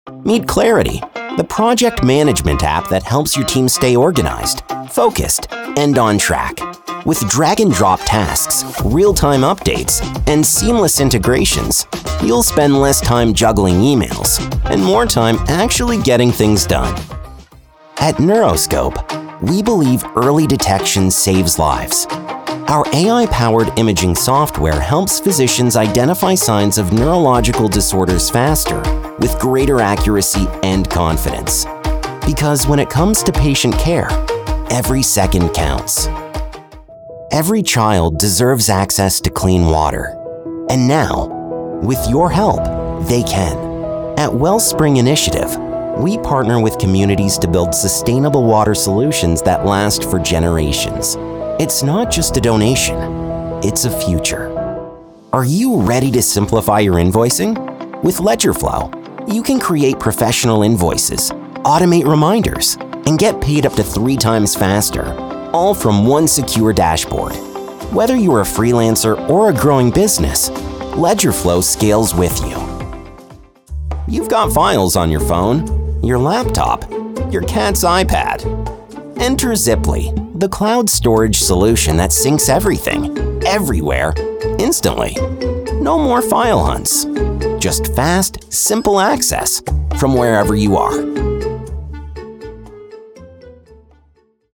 Explainer & Whiteboard Video Voice Overs
Yng Adult (18-29) | Adult (30-50)